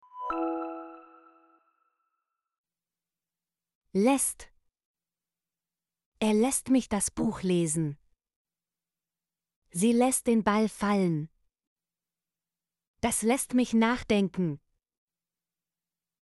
lässt - Example Sentences & Pronunciation, German Frequency List